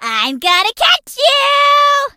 colette_ulti_vo_02.ogg